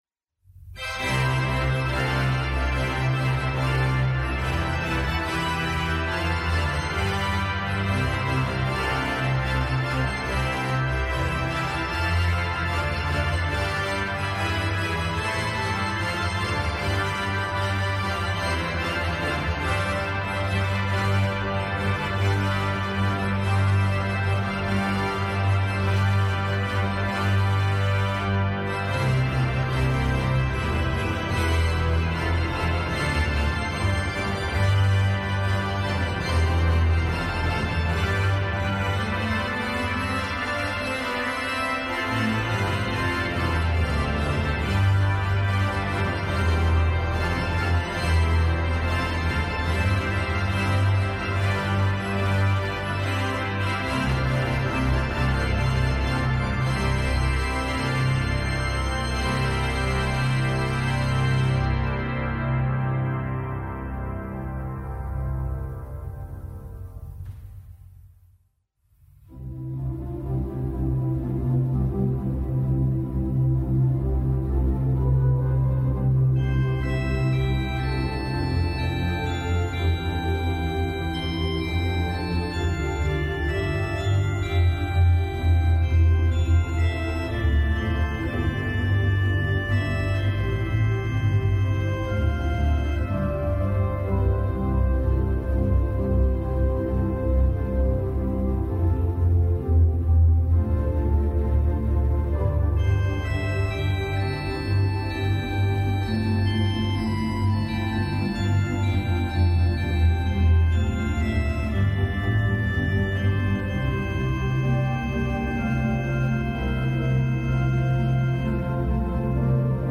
Kapitelsmesse am Freitag der zweiten Osterwoche
Kapitelsmesse aus dem Kölner Dom am Freitag der zweiten Osterwoche.